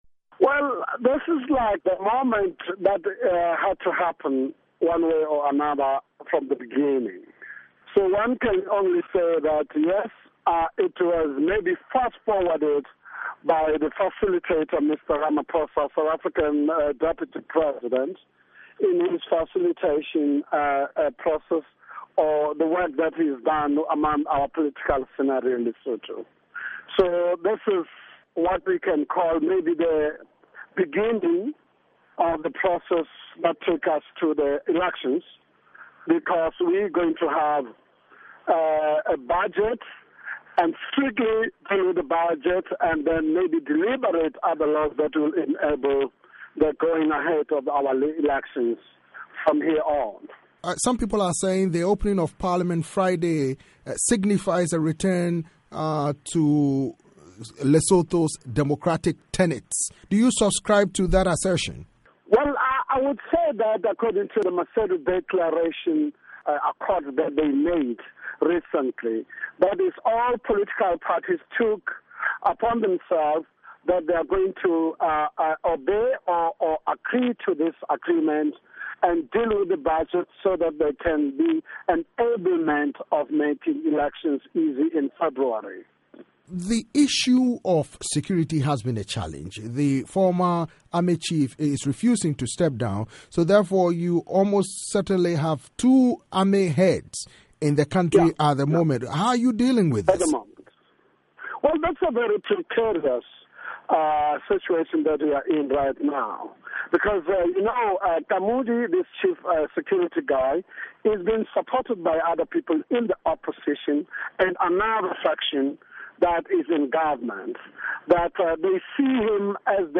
interview with Khoabane Theko, Chief Whip of Lesotho’s senate